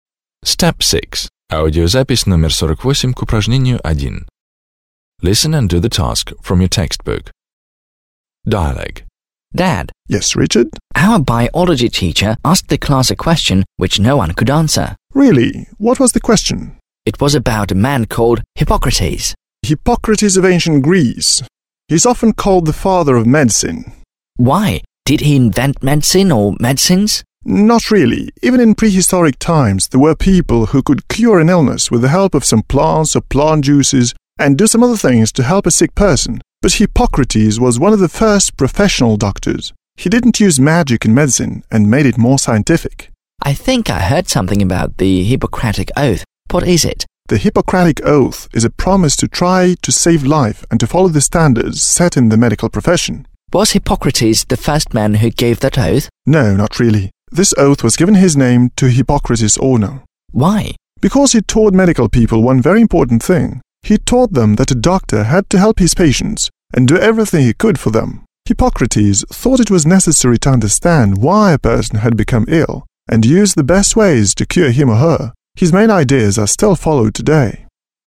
1. Listen to the dialogue between Richard and his father, (48), and complete the sentences (1-4).